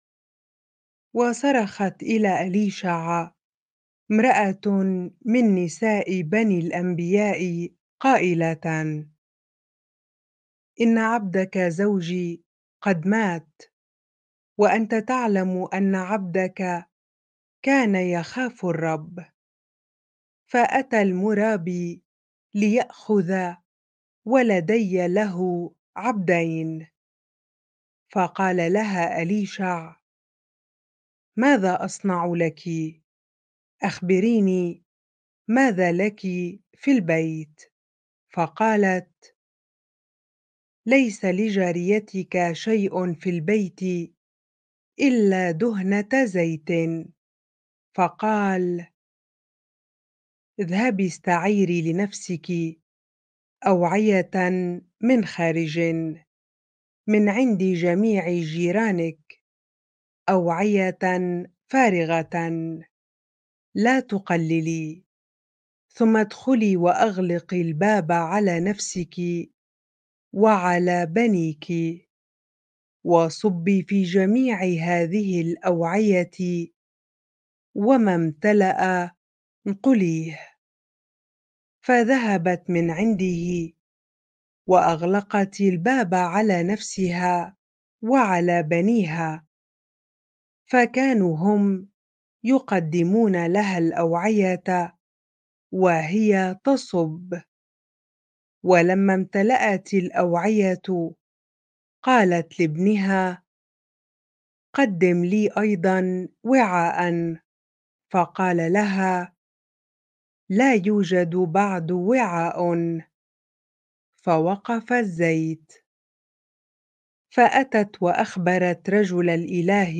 bible-reading-2 Kings 4 ar